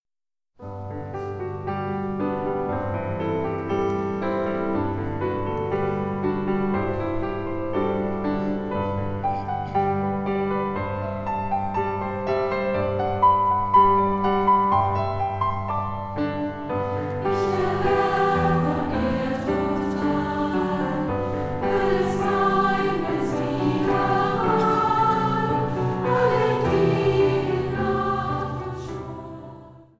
Chor, Orchester